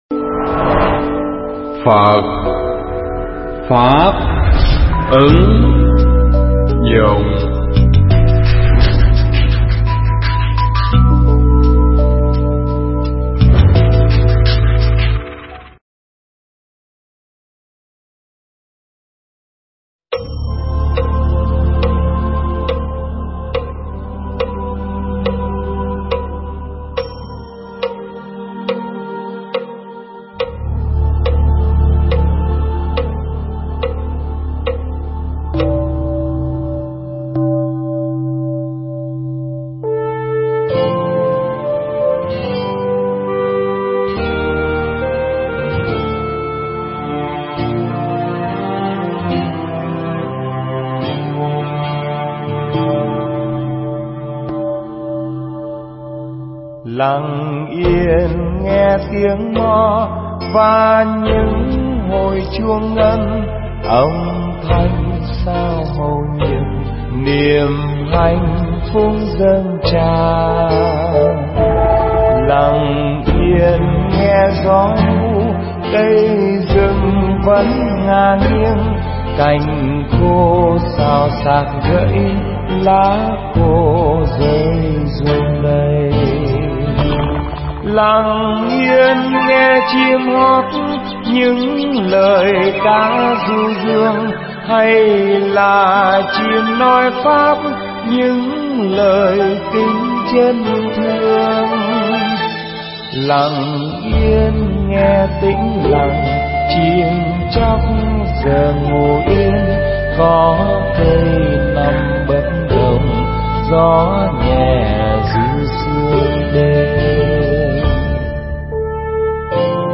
Mp3 Pháp Thoại Tâm An Lạc (KT13) – Hòa Thượng Thích Trí Quảng giảng tại Tu Viện Tường Vân trong Khóa Tu An Lạc Lần 13, (ngày 28 tháng Giêng năm Nhâm Thìn)